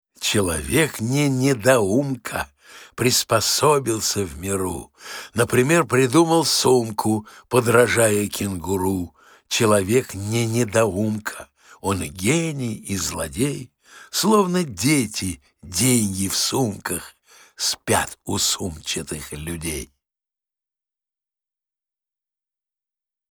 Gaft-Sumka-chitaet-avtor-stih-club-ru.mp3